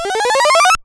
その他の効果音 試聴ダウンロード ｜ seadenden 8bit freeBGM